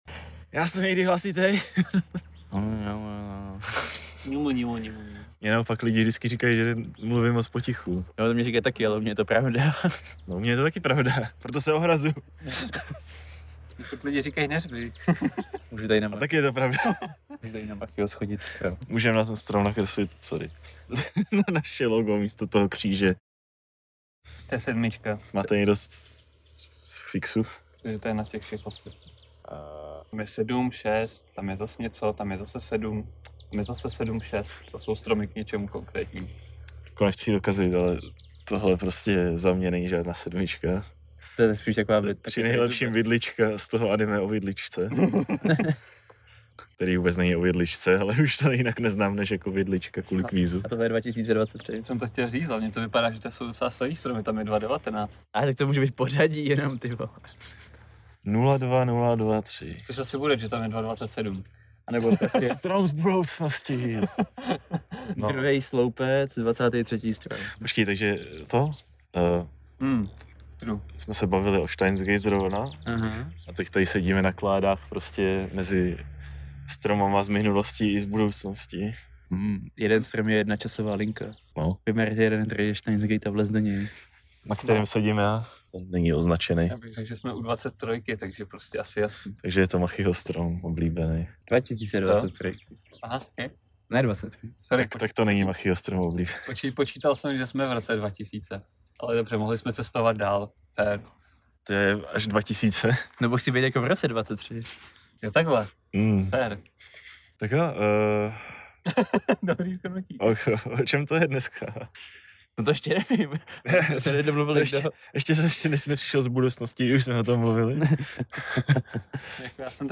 Smějeme se každé blbosti a navzájem po sobě házíme plyšáky.